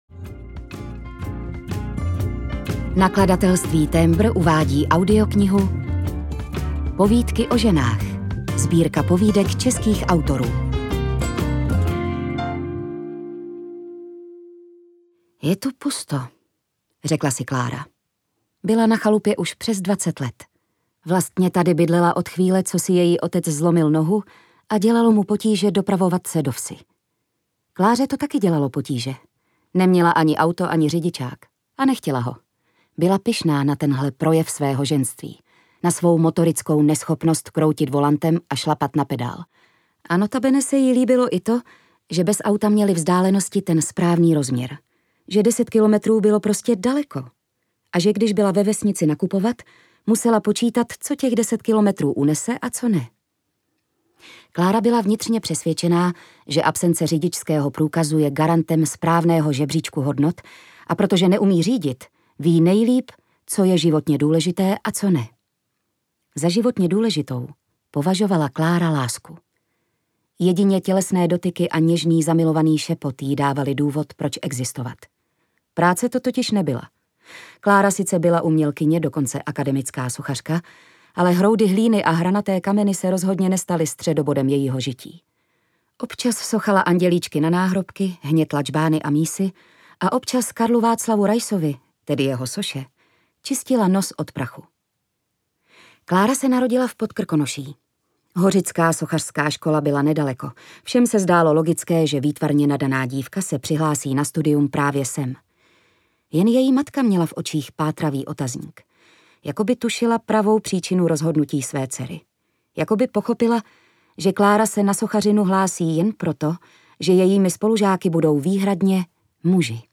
Povídky o ženách audiokniha
Ukázka z knihy
• InterpretJitka Ježková